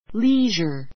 leisure A2 líːʒər り ージャ ｜ léʒə れ ジャ 名詞 暇 ひま ; 余暇 よか , レジャー lead a life of leisure lead a life of leisure 余暇のある生活を送る[自由に使える時間がある忙しすぎない暮らしをする] ⦣ × a leisure, × leisure s としない.